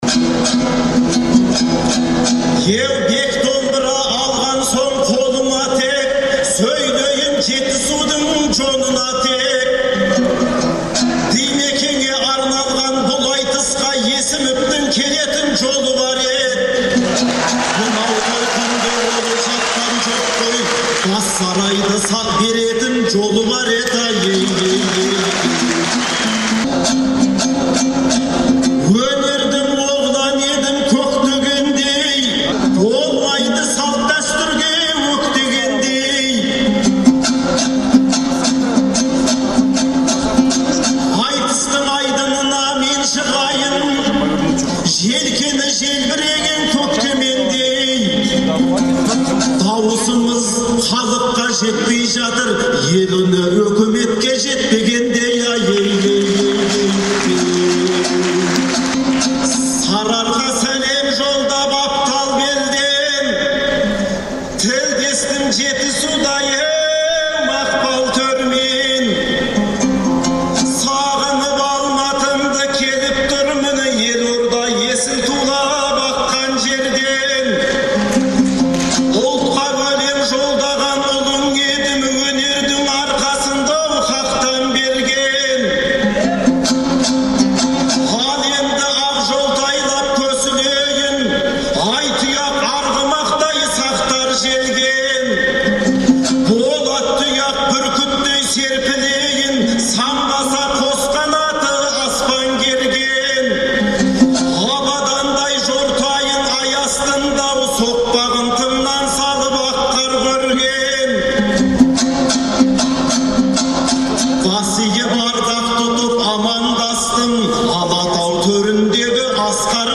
Алматыдағы Балуан Шолақ атындағы спорт сарайында ақпанның 11-і мен 12-сі күні «Қонаевтай ер қайда» деген атпен өткен айтыста